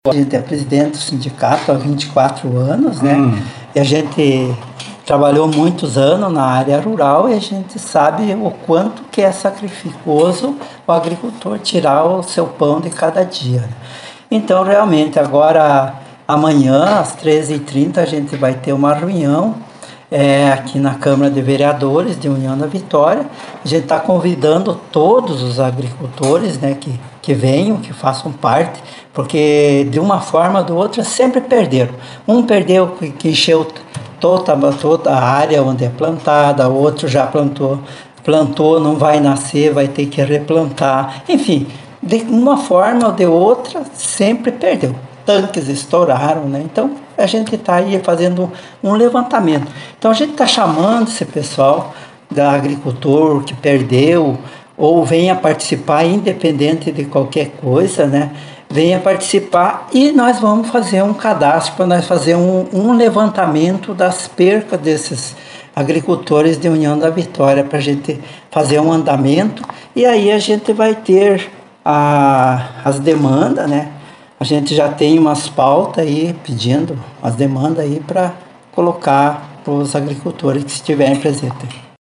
O vereador Cortelini é quem teve a iniciativa. Acompanhe o áudio da entrevista.